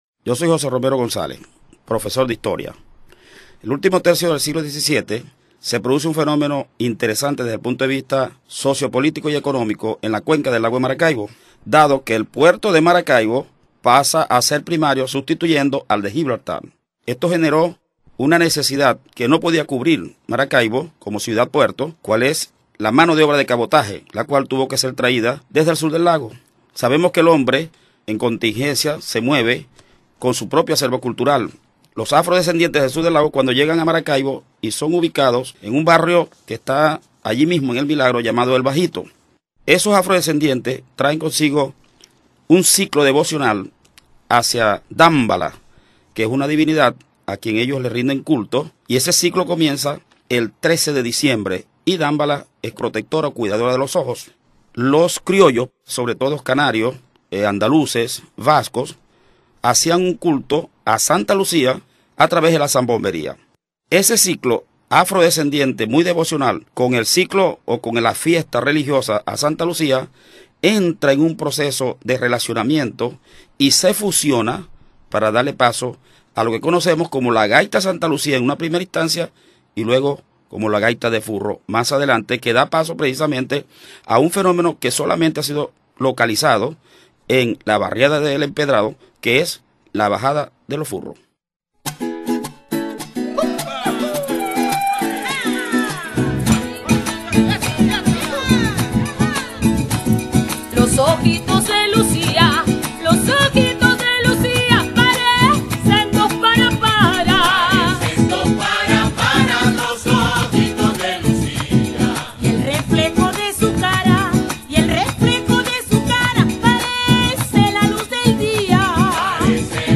Gaita A Santa Lucia